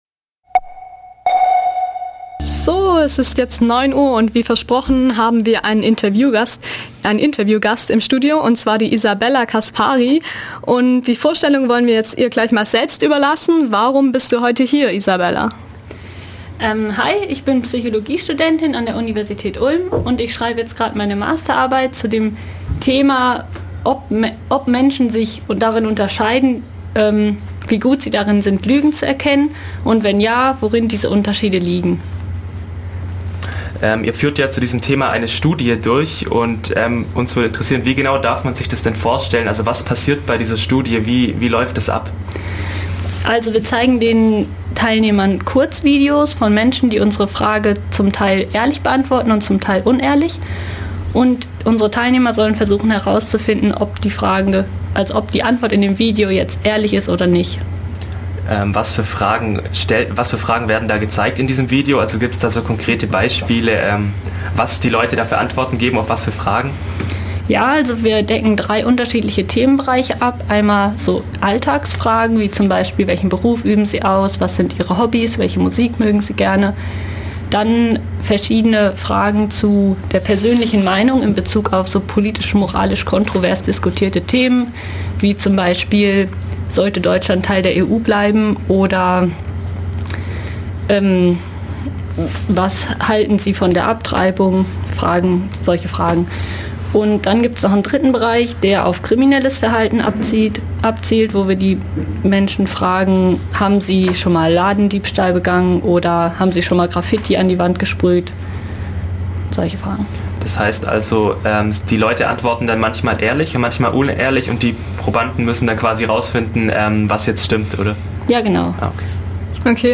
Radio
interview_luegen.mp3